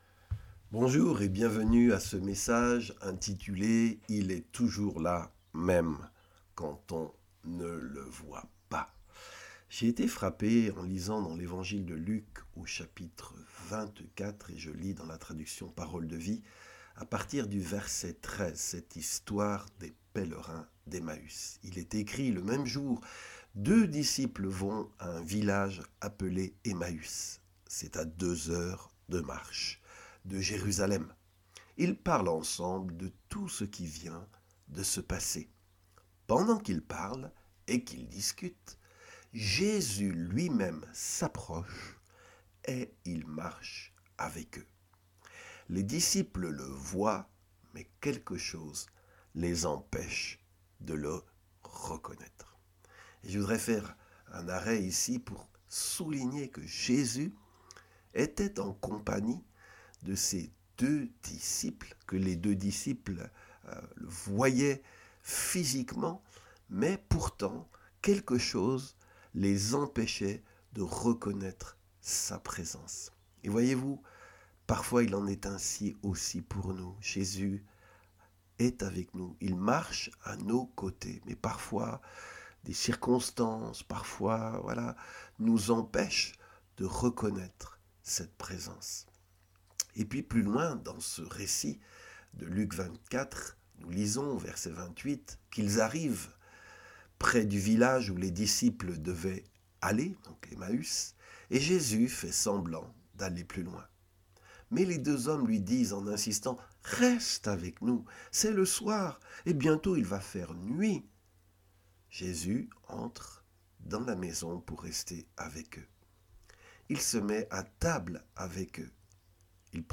Méditation et enseignement biblique sur les pèlerins d'Emmaüs